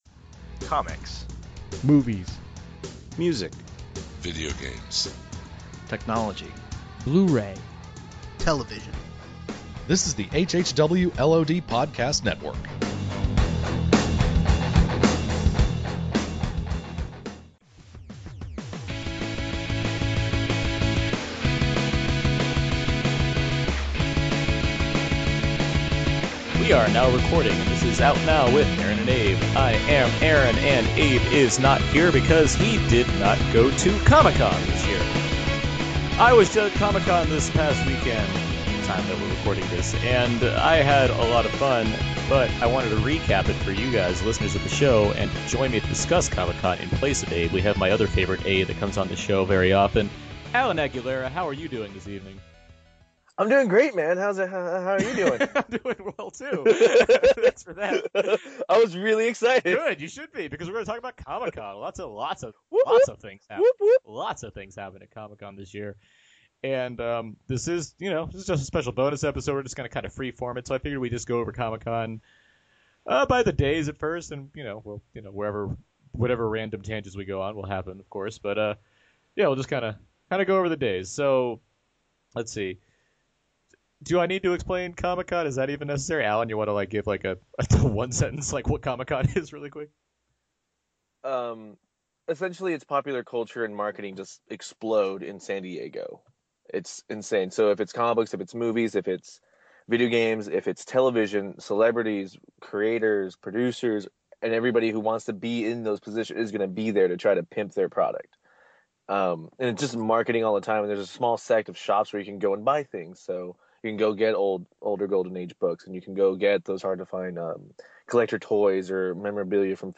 It is a lengthier discussion than anticipated, but certainly packed with info.